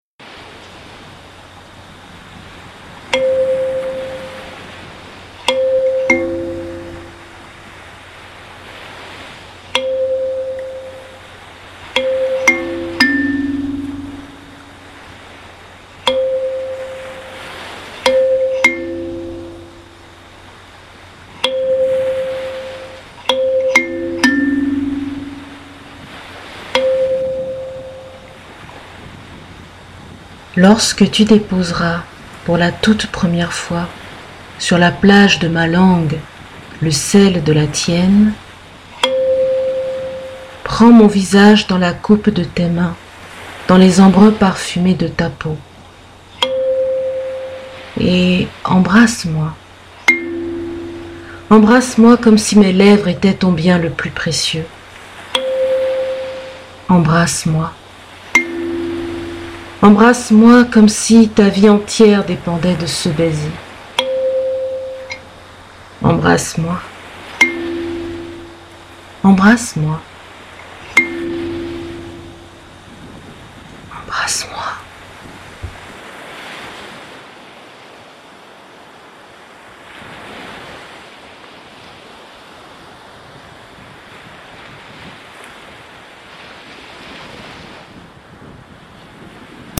Pour commencer l’année en douceur, je vous offre mon tout premier poème de 2017, mis en voix et en son.